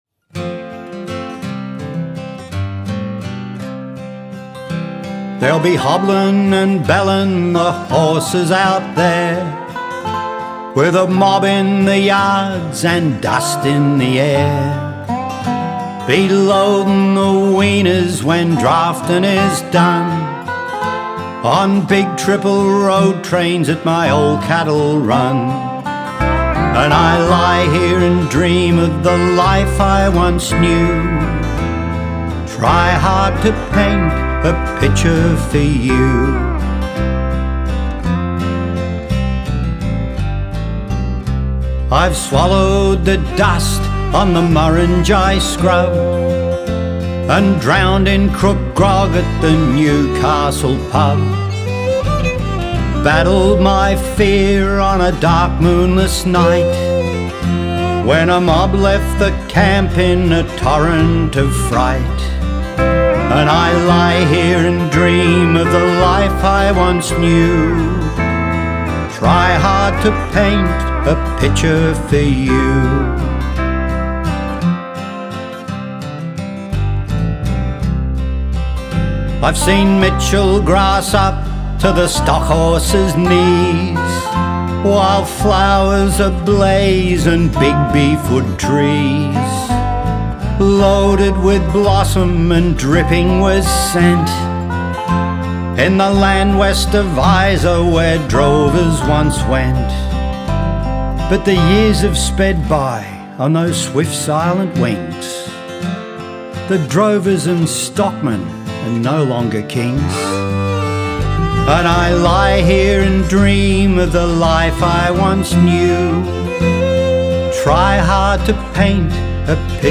a true Bush Ballad